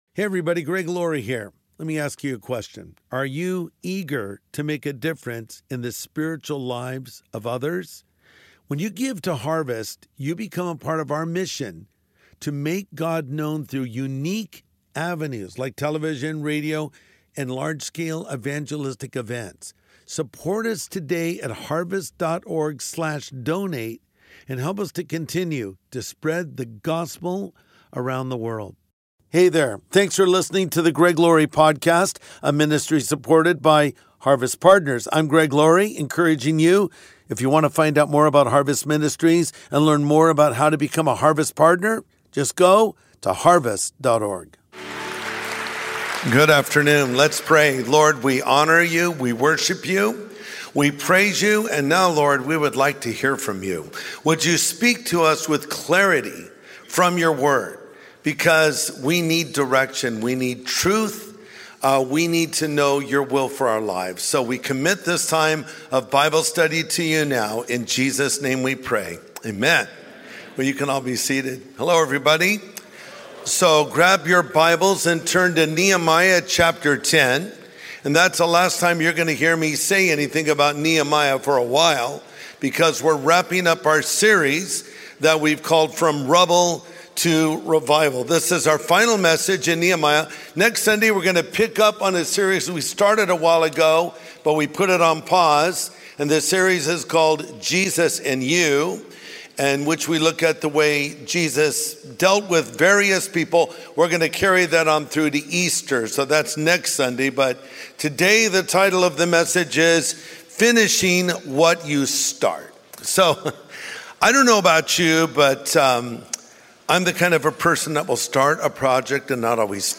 Finishing What You Start | Sunday Message